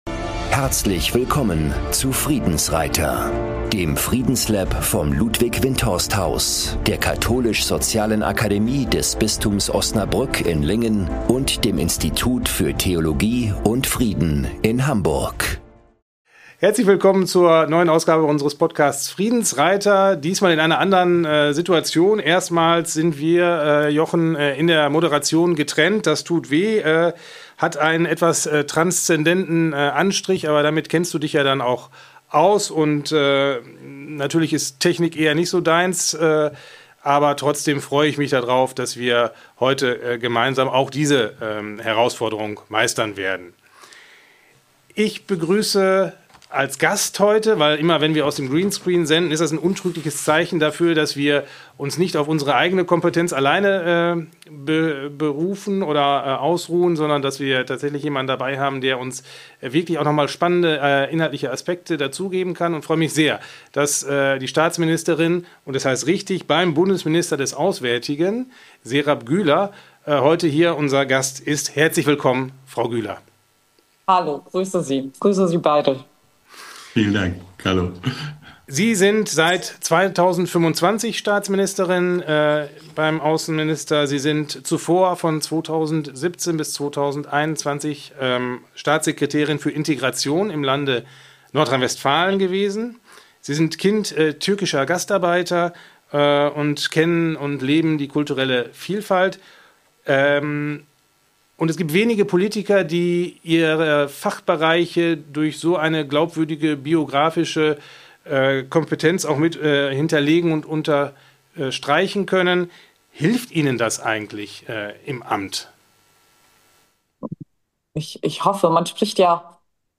In diesem Gespräch wirbt sie für eine realistische Außenpolitik, die Glaubwürdigkeit zurückgewinnt, ohne in Zynismus zu verfallen.
Ein Gespräch über Außenpolitik ohne Illusionen – über Realismus ohne Zynismus, Werte ohne Selbstgerechtigkeit und Interessen ohne Verdrängung.